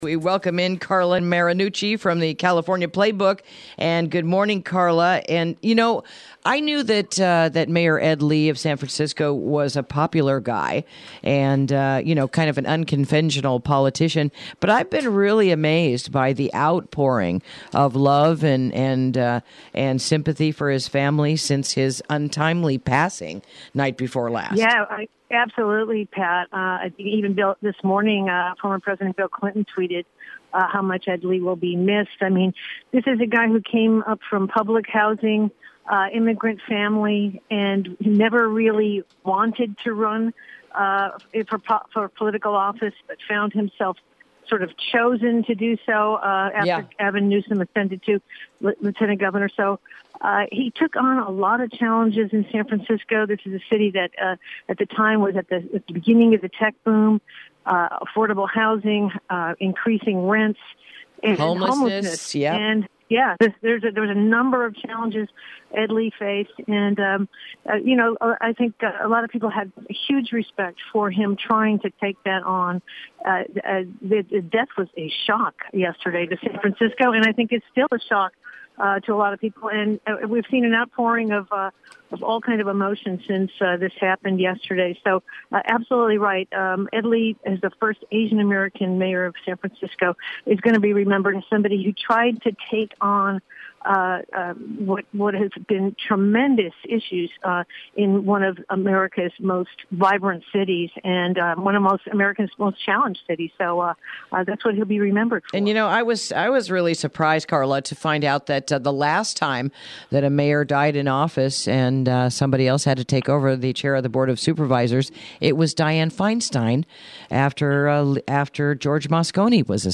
Interview: A Look Around the Golden State | KSRO 103.5FM 96.9FM & 1350AM